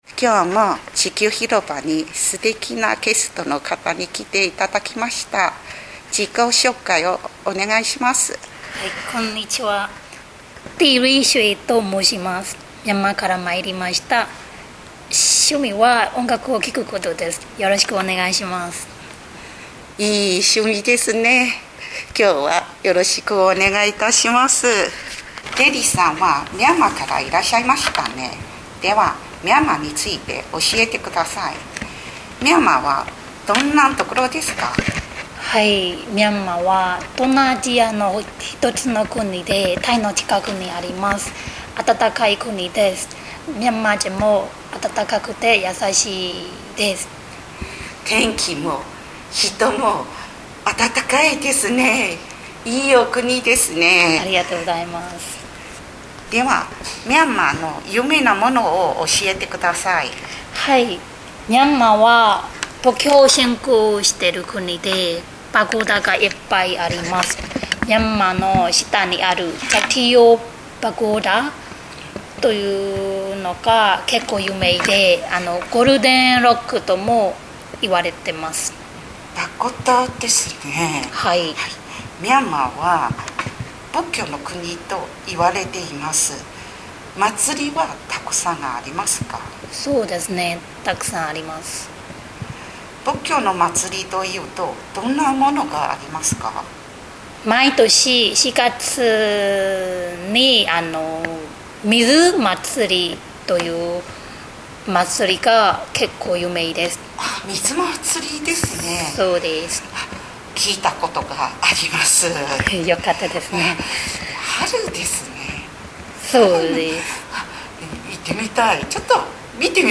2025年1月～3月インタビュー